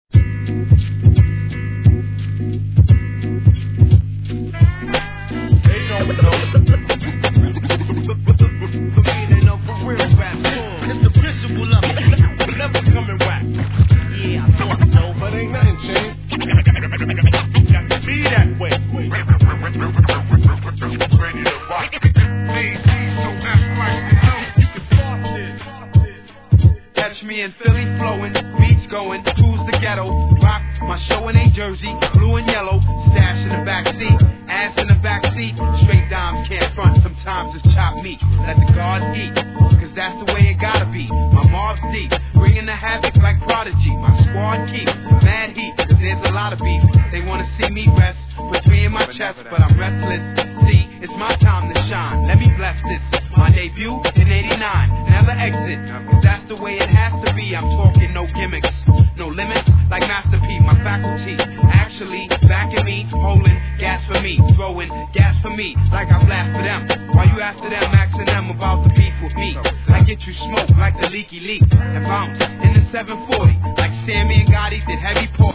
1. HIP HOP/R&B
フィラデルフィア産アンダーグランド!